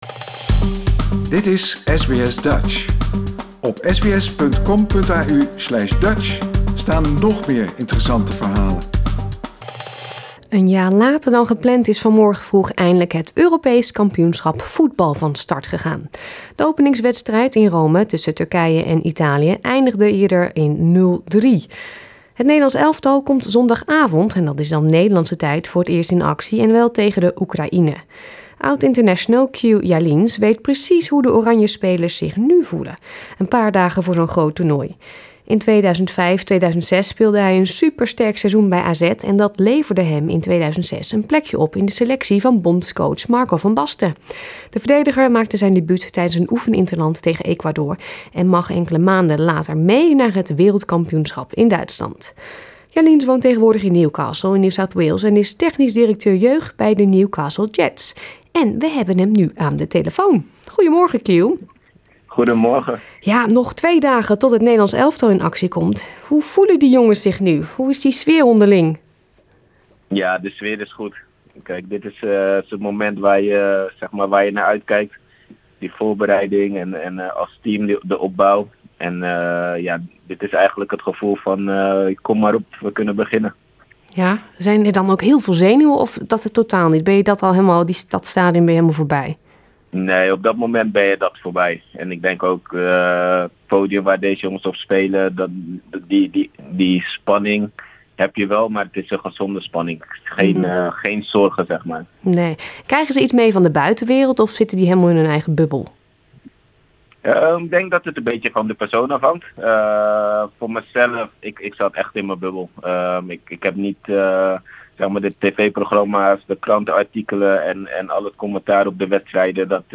Oud-international Kew Jaliens speelde op het WK van 2006 en weet precies hoe de Oranje spelers zich voorbereiden op zo'n toernooi. Wij spraken Kew, die tegenwoordig technisch directeur jeugd is bij de Newcastle Jets en Northern NSW Soccer.